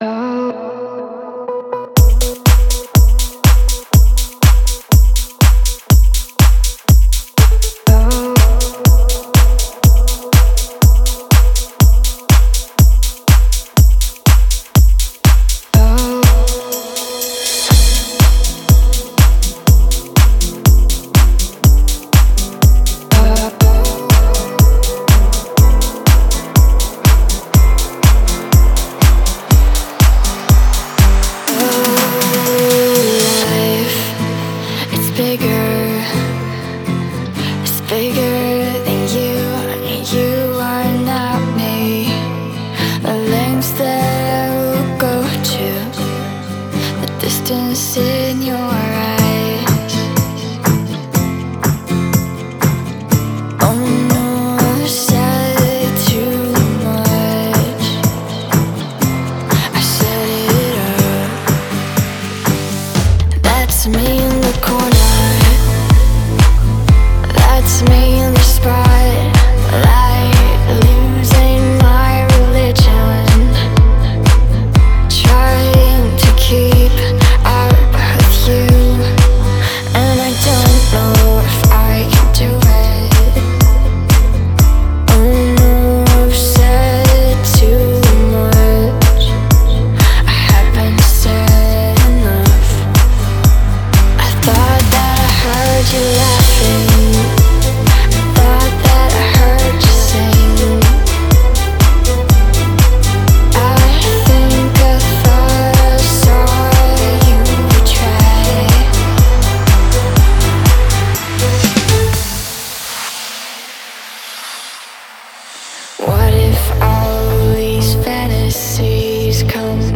Стиль: Deep House / Dance / Pop / Club House / Vocal House